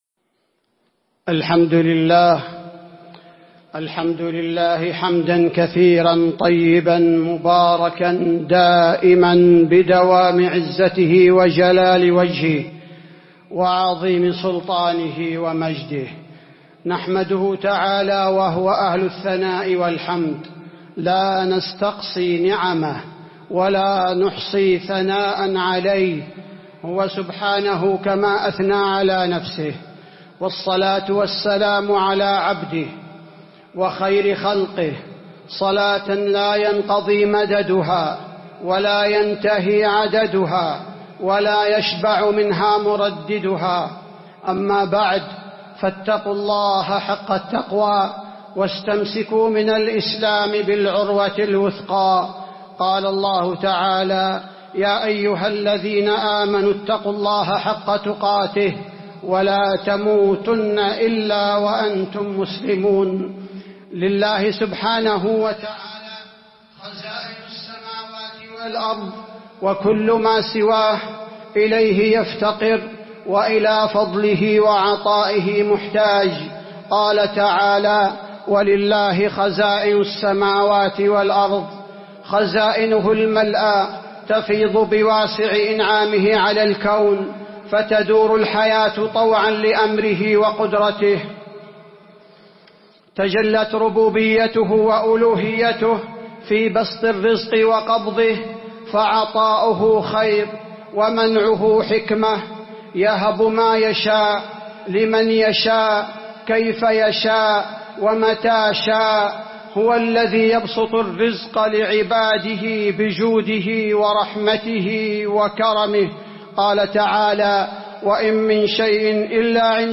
خطبة الاستسقاء - المدينة - الشيخ عبدالباري الثبيتي
تاريخ النشر ٤ ربيع الثاني ١٤٤٢ هـ المكان: المسجد النبوي الشيخ: فضيلة الشيخ عبدالباري الثبيتي فضيلة الشيخ عبدالباري الثبيتي خطبة الاستسقاء - المدينة - الشيخ عبدالباري الثبيتي